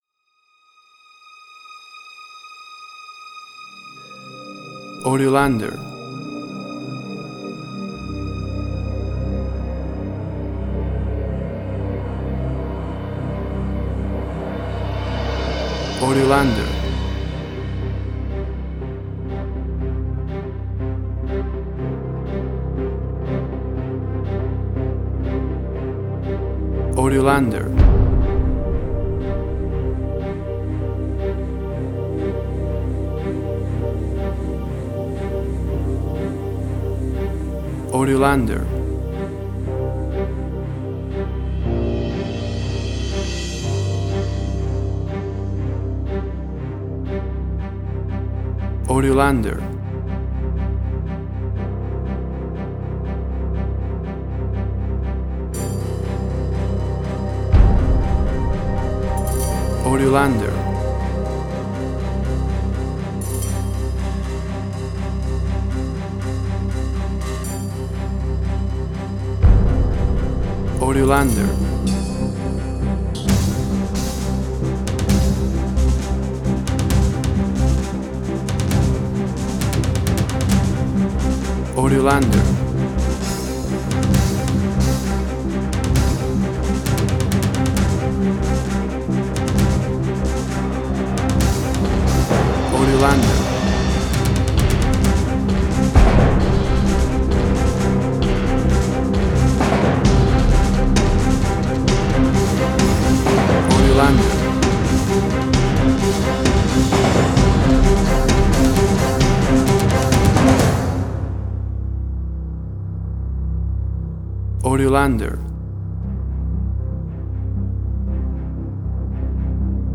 Suspense, Drama, Quirky, Emotional.
WAV Sample Rate: 16-Bit stereo, 44.1 kHz